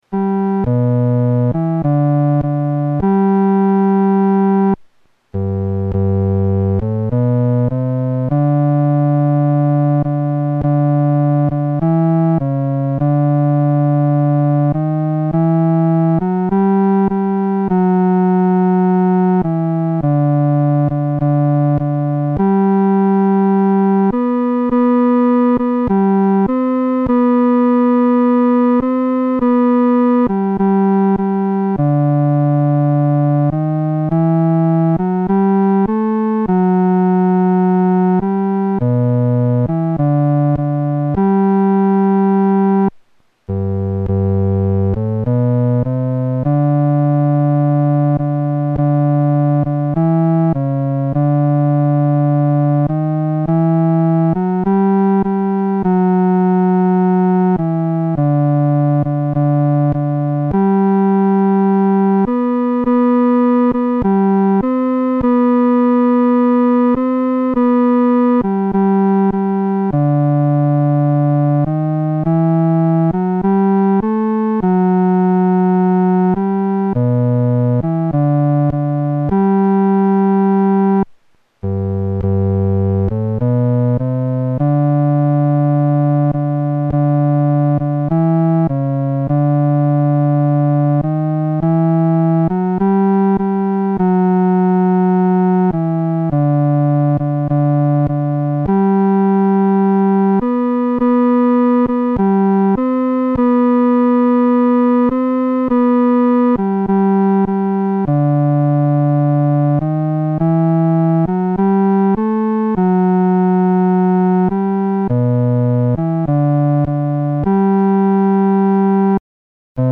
伴奏
男低
诗班在二次创作这首诗歌时，要清楚这首诗歌音乐表情是亲切、温存地。